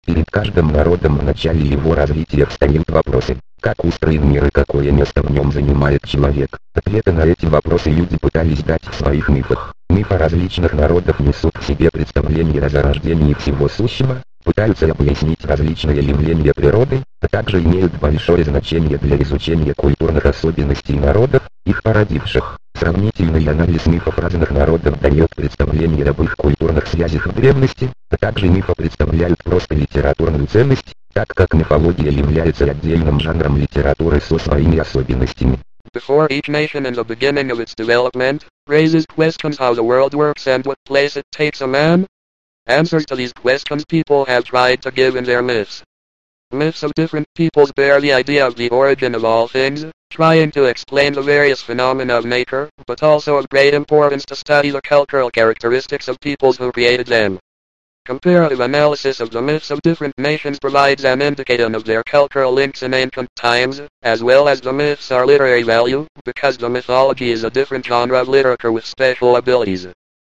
Речевой синтезатор Chatterbox - это русско-английский синтезатор речи, построенный на платформе SAPI4.
Он может как читать всё одним русскоязычным голосом, так и, анализируя текст, читать русские слова русскоязычным голосом, а английские - англоязычным, причём английский голос имеет функцию обособленной настройки скорости речи.
Синтезатор обладает разборчивой и выразительной речью, как в русском, так и в английском варианте.